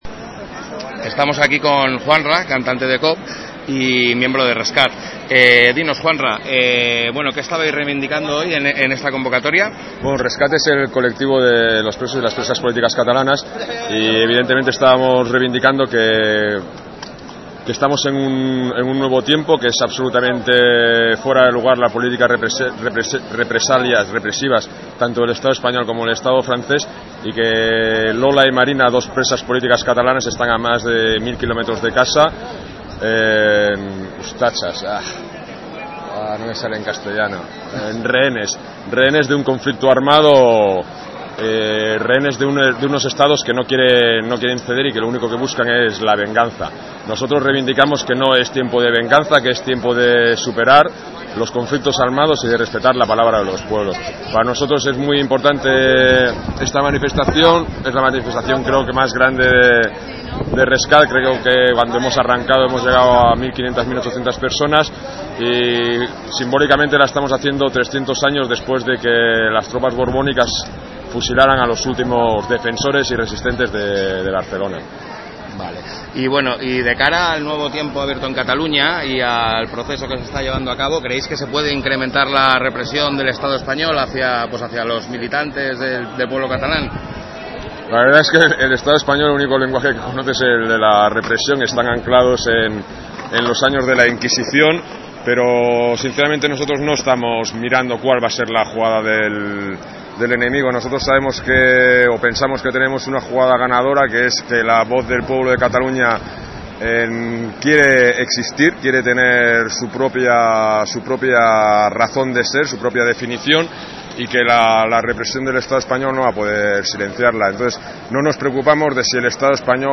La Haine entrevista